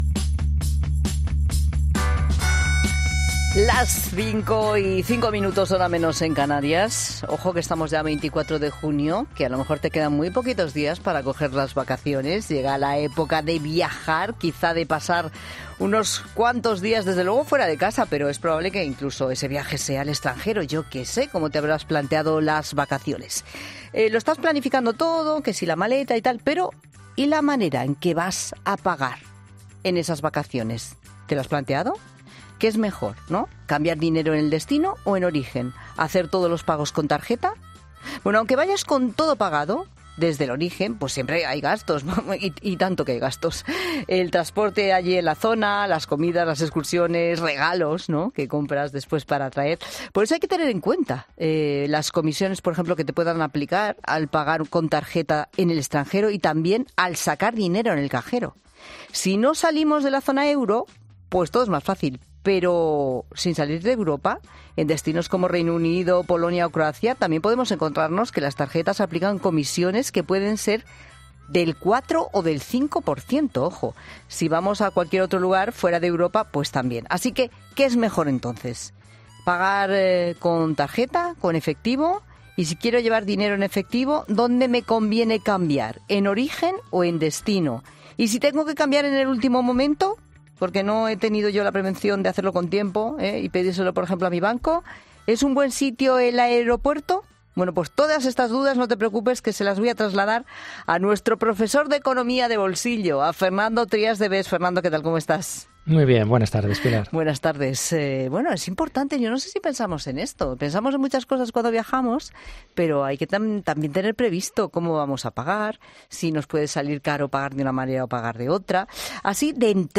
Tarjeta, móvil o efectivo: El economista Fernando Trías de Bes explica cuál es la mejor forma, más rentable y segura de pagar en el extranjero